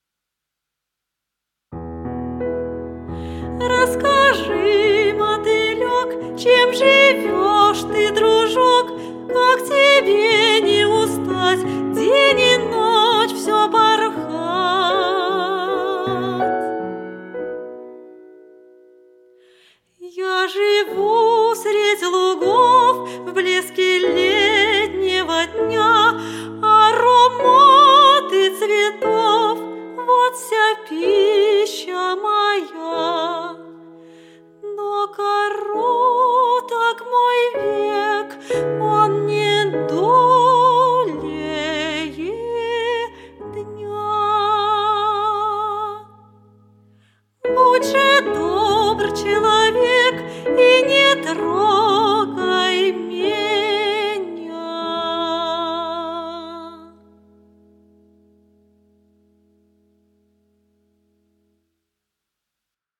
Вокальные сочинения для детей композиторов - классиков: